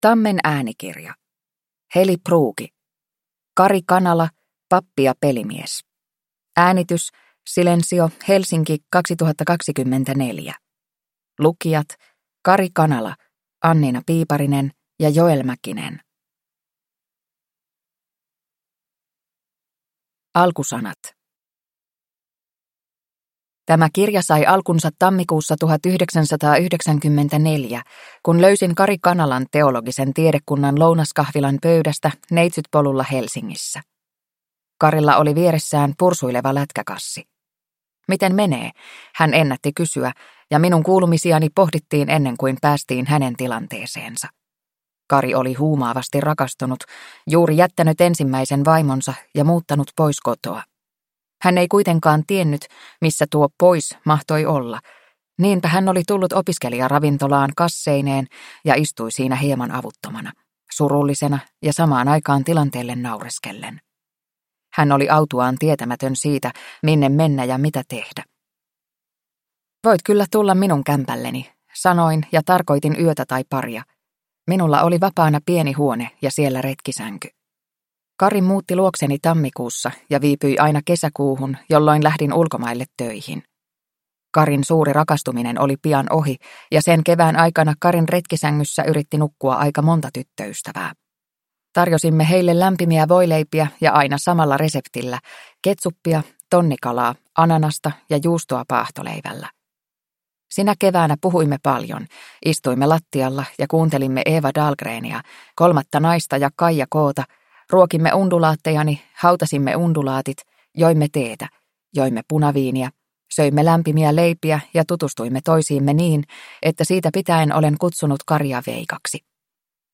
Kari Kanala - Pappi ja pelimies (ljudbok) av Heli Pruuki